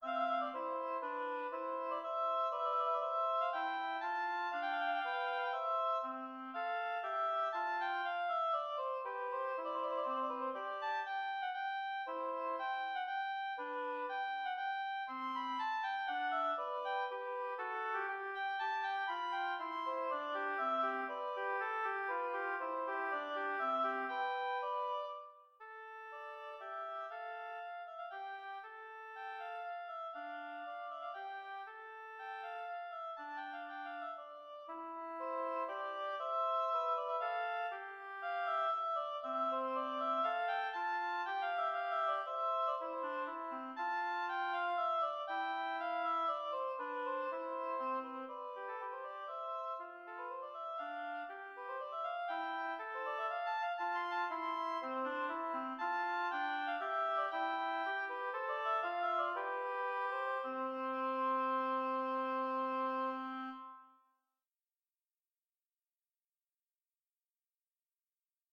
Intermediate oboe duet